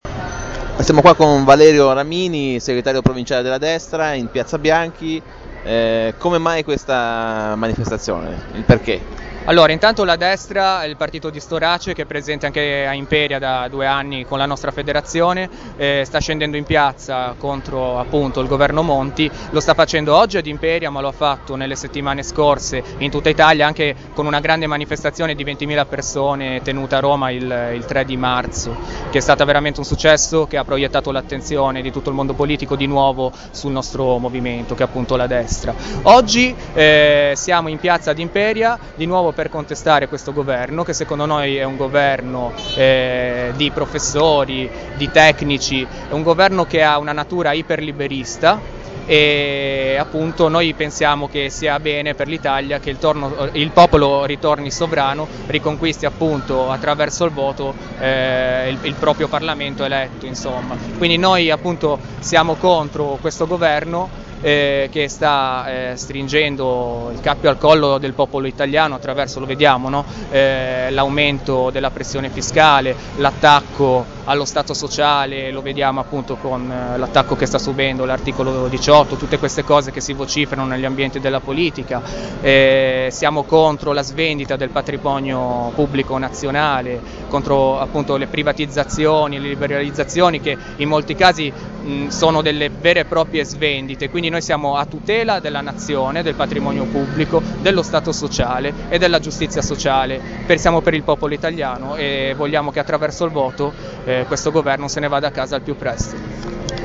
Circa un' ottantina di persone (tra gli iscritti, i militanti e simpatizzanti, ndr) hanno partecipato al sit-in organizzato in piazza Bianchi ad Imperia. Il movimento di Francesco Storace ha annunciato dalla Piazza di essere intenzionato a partecipare alle prossime consultazioni amministrative del 2013.